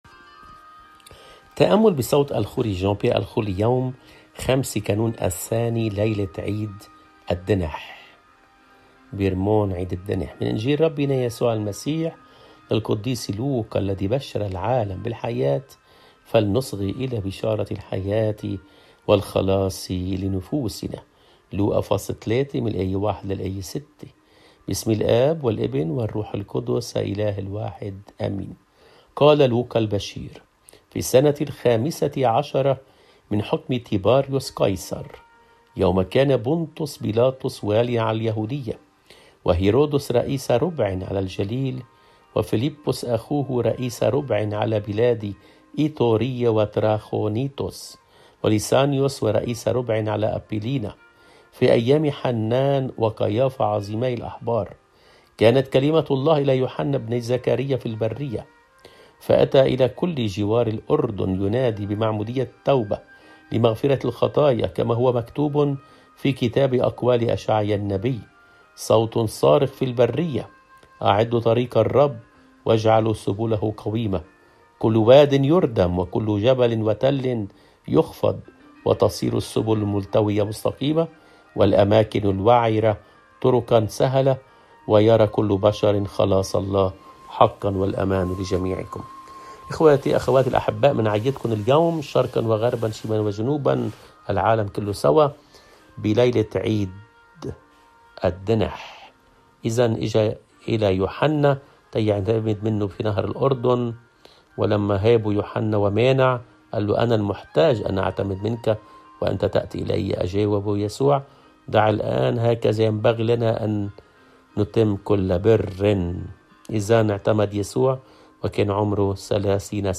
الإنجيل